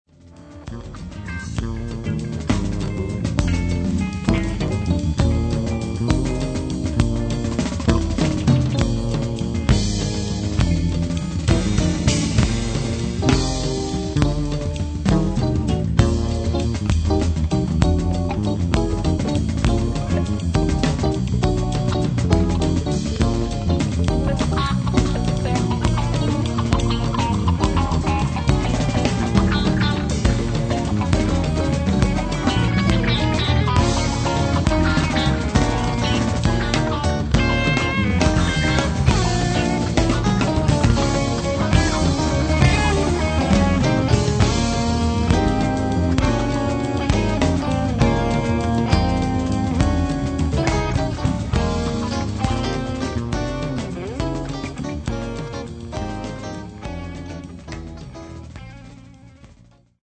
and bass, guitars, keyboard, sax and trumpet.